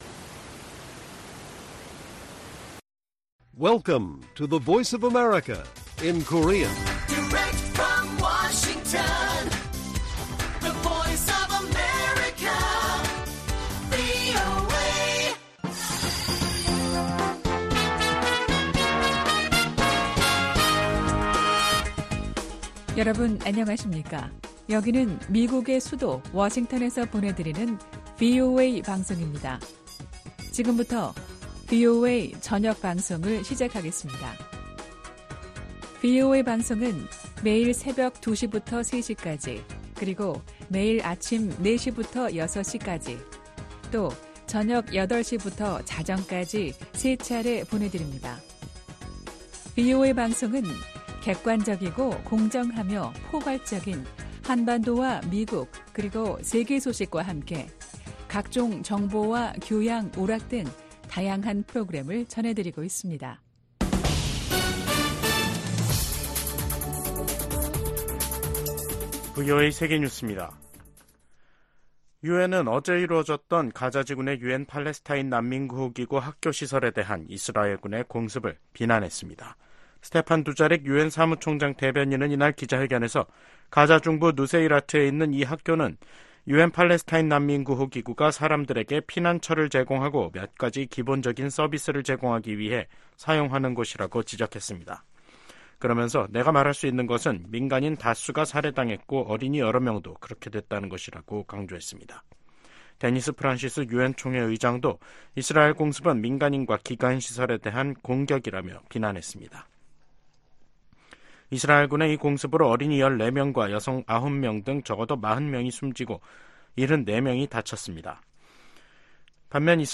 VOA 한국어 간판 뉴스 프로그램 '뉴스 투데이', 2024년 6월 7일 1부 방송입니다. 미국, 한국, 일본 3국의 협력은 인도태평양의 안보 구조와 정치 구조를 위한 ‘근본적인 체제’라고 미 국가안보부보좌관이 평가했습니다. 블라디미르 푸틴 러시아 대통령은 최근 세계 주요 뉴스통신사들과의 인터뷰에서 한국이 우크라이나에 무기를 공급하지 않고 있다고 이례적으로 감사 표시를 했습니다.